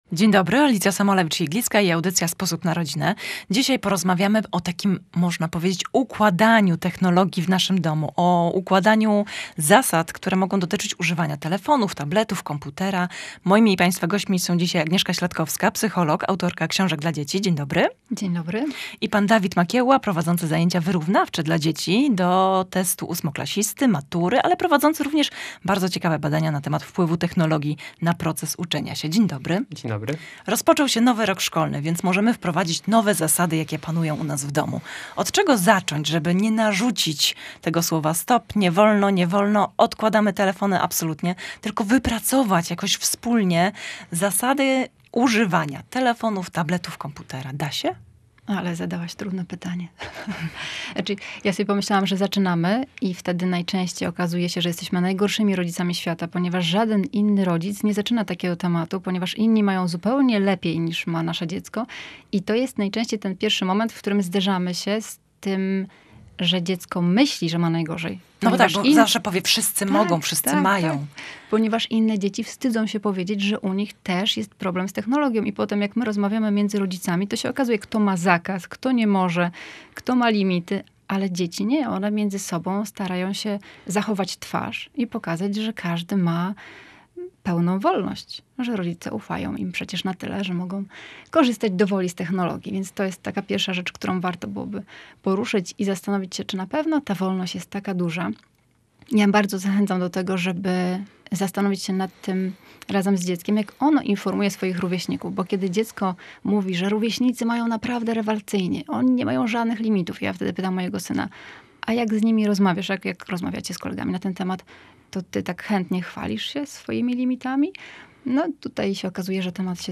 W audycji „Sposób na rodzinę” rozmawialiśmy zarówno o korzystaniu z telefonów i tabletów, jak i propozycjach na spędzenie wspólnego, rodzinnego czasu na świeżym powietrzu.